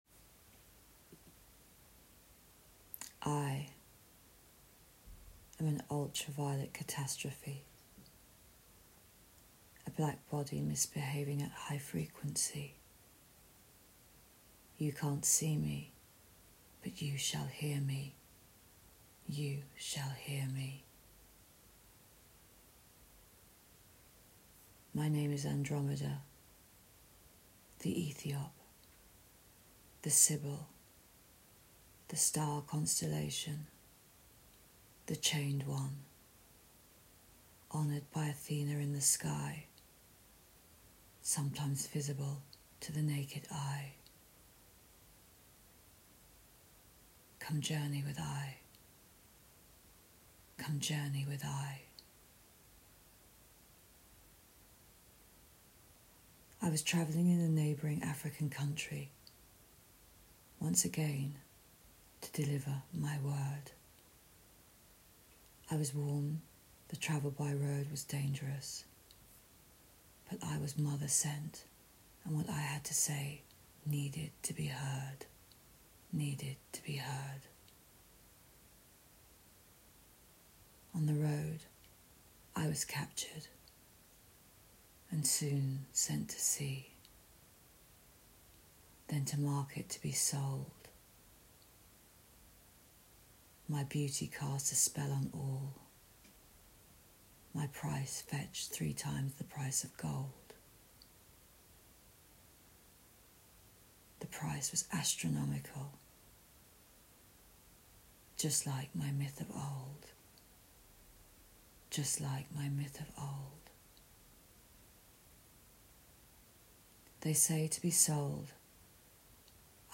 AUDIO POESIS – SOUND INSTALLATION: